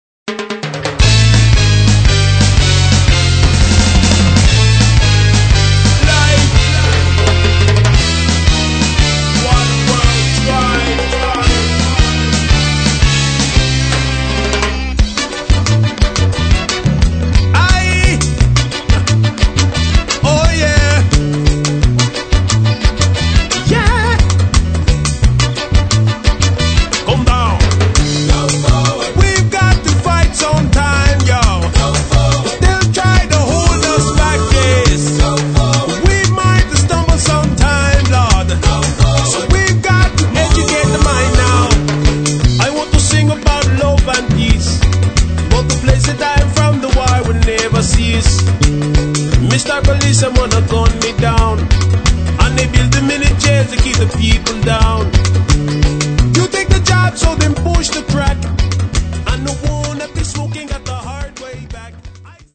Marley style reggae